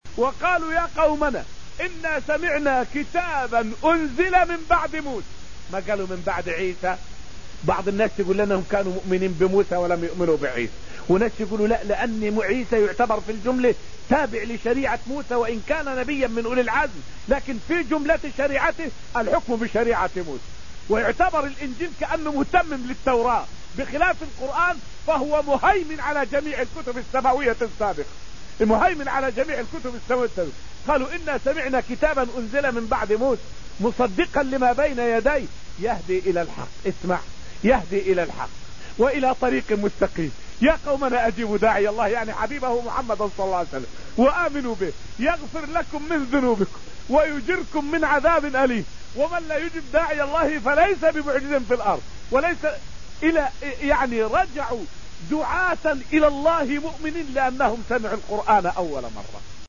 فائدة من الدرس التاسع عشر من دروس تفسير سورة الحديد والتي ألقيت في المسجد النبوي الشريف حول دلالة قوله تعالى: {إنا سمعنا كتابا أنزل من بعد موسى}.